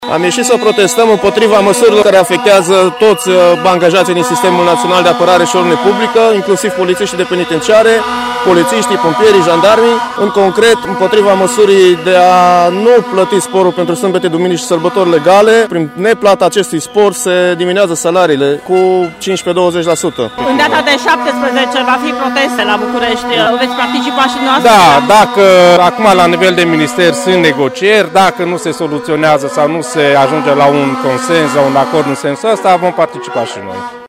O parte dintre cei care au ieșit astăzi în stradă la Târgu Mureș sunt polițiști pensionari, dar și polițiști de penitenciar. Dacă la negocierile care au loc astăzi între sindicaliști și reprezentanții Ministerului de Interne nu vor fi găsite soluții pentru angajați, protestele vor continua vineri la București, spun polițiștii de penitenciar din Târgu Mureș: